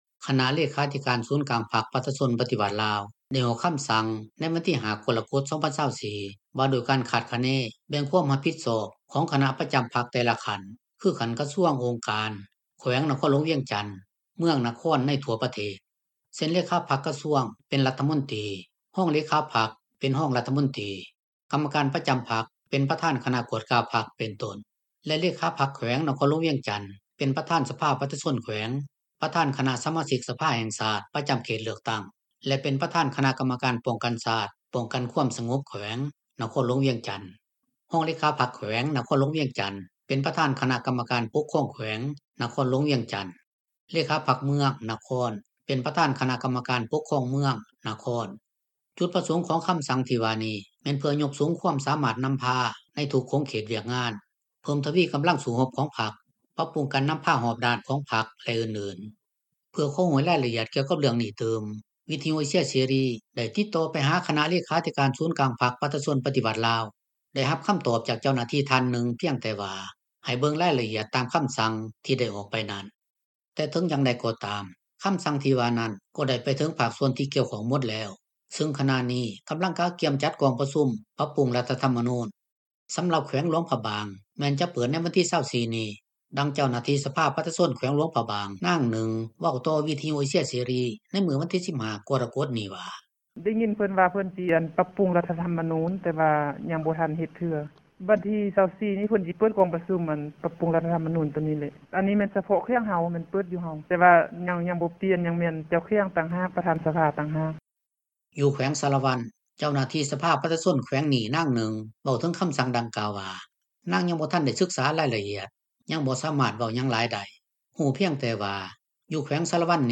ແຕ່ເຖິງຢ່າງໃດກໍຕາມ ຄໍາສັ່ງທີ່ວ່ານັ້ນ ກໍໄດ້ໄປເຖິງພາກສ່ວນທີ່ກ່ຽວໝົດແລ້ວ ຊຶ່ງຂະນະນີ້ກໍາລັງກະກຽມຈັດກອງປະຊຸມ ປັບປຸງລັດຖະທໍາມະນູນ. ສໍາລັບແຂວງຫຼວງພະບາງ ແມ່ນຈະເປີດໃນວັນທີ 24 ນີ້ ດັ່ງເຈົ້າໜ້າທີ່ສະພາປະຊາຊົນ ແຂວງຫຼວງພະບາງ ນາງໜຶ່ງເວົ້າຕໍ່ວິທະຍຸເອເຊັຽເສຣີໃນມື້ວັນທີ 15 ກໍລະ ກົດນີ້ວ່າ: